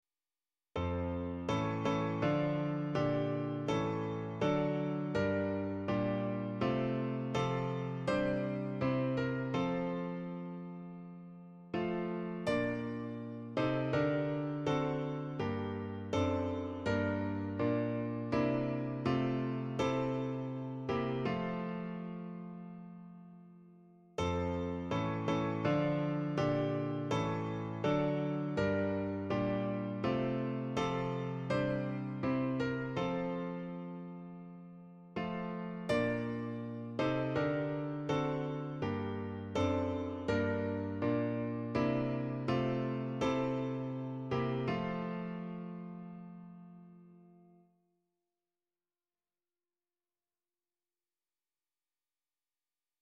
Founder's Day Service
The angelic voices of our choir presented two hymns and representatives of the Christian Fellowship presented the readings from the bible.
Songs sung during service: School Song | School Hymn |